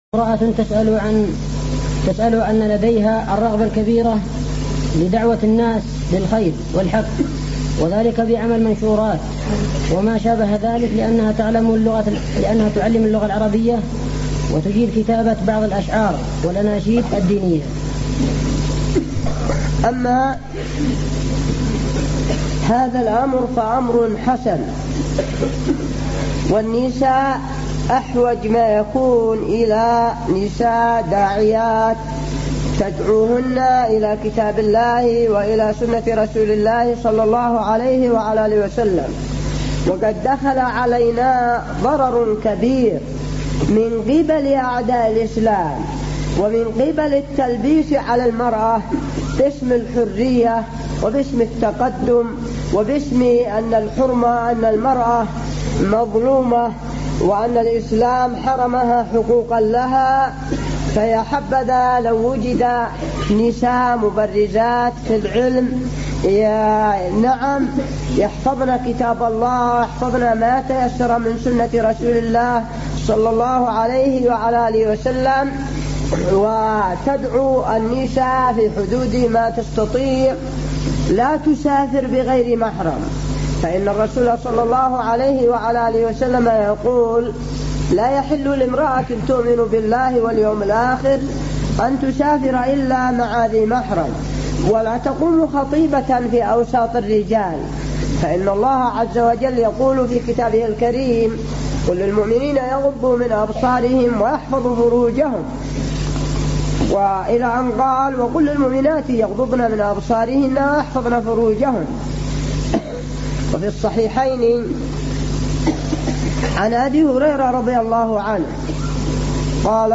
امرأة تسأل عن الرغبة الكبيرة لدعوة الناس للخير والحث وذلك بعمل منشورات وما شابه ذلك لأنها تعلم اللغة العربية وتجيد كتابة بعض الأشعار والأناشيد الدينية ؟